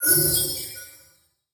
bullet_heal.wav